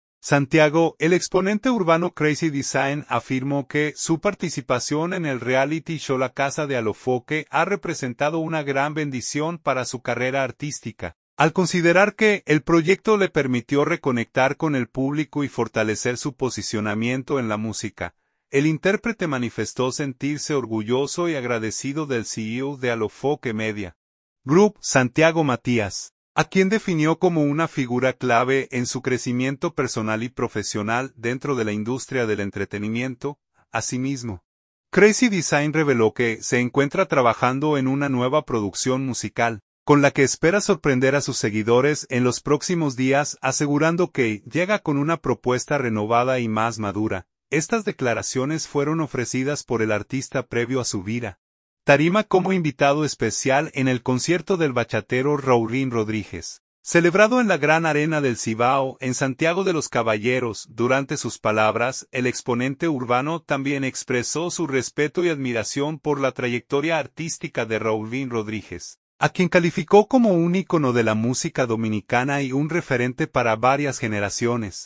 Estas declaraciones fueron ofrecidas por el artista previo a subir a tarima como invitado especial en el concierto del bachatero Raulín Rodríguez, celebrado en la Gran Arena del Cibao, en Santiago de los Caballeros.